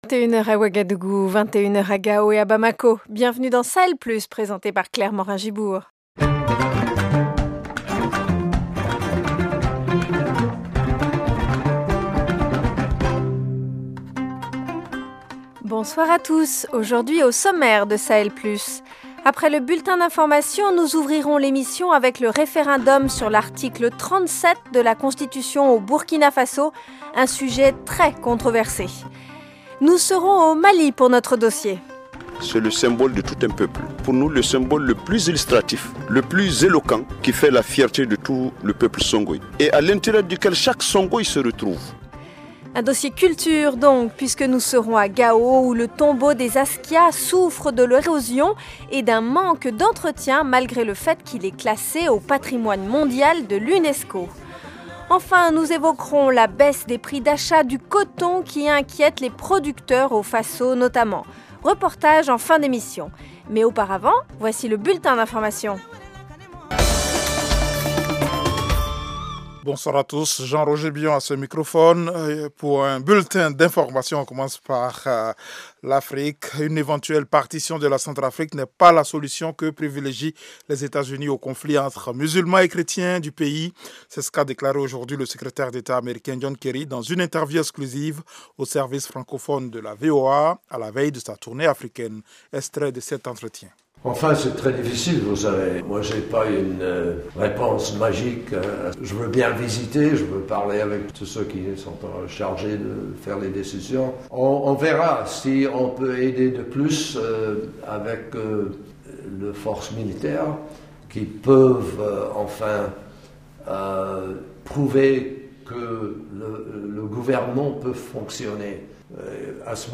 Dossier : à Gao au Mali, le Tombeau des Askias souffre de l’érosion et d’un manque d’entretien malgré le fait qu’il est classé au patrimoine mondial de l’Unesco. African Voice : les producteurs de coton burkinabè s’inquiètent de la baisse des prix d’achat. Reportage